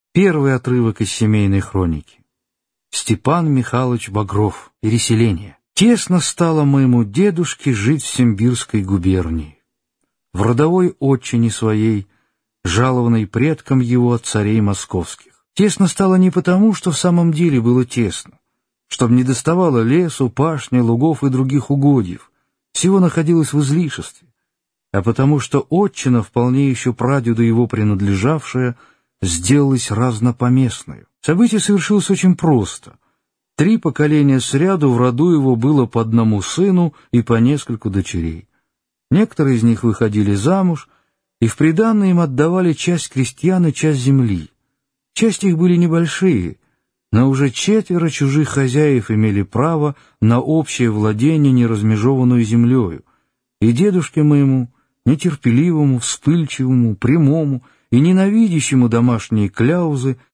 Аудиокнига Семейная хроника | Библиотека аудиокниг